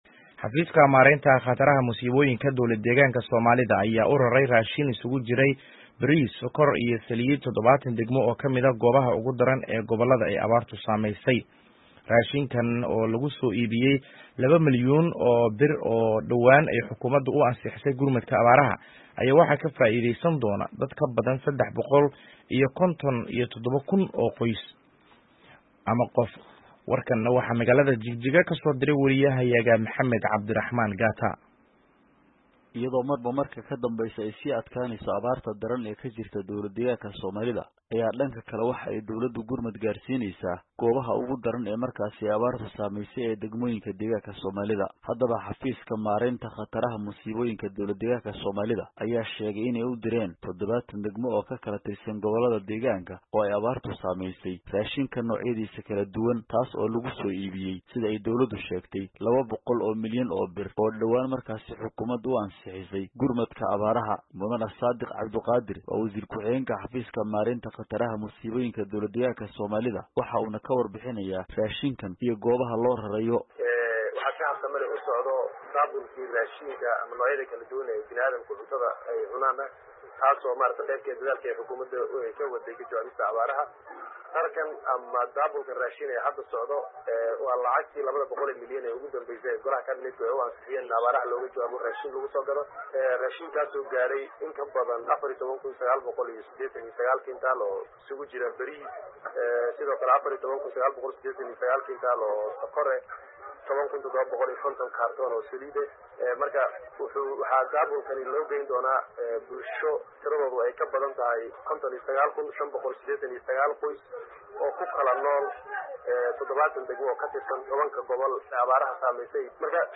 Warbixinta Jigjiga